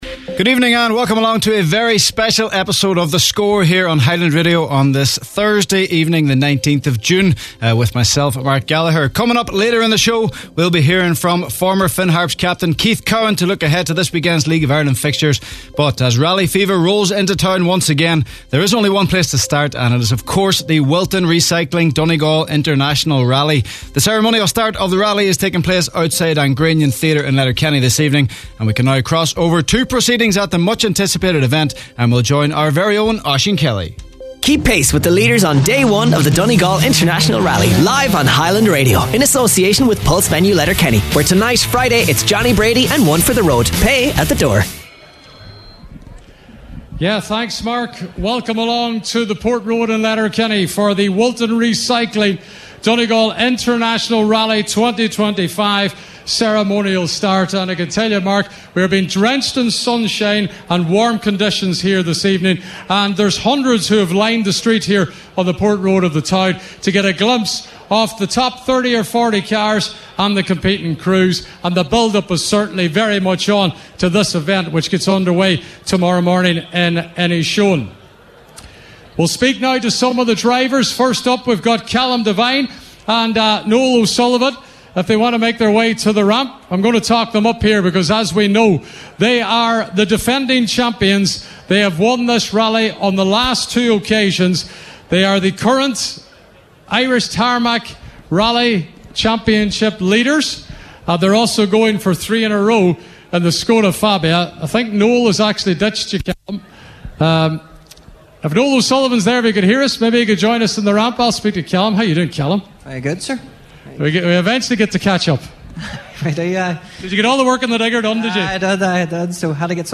The Score – 19/06/25 LIVE from the Donegal Rally Ceremonial Start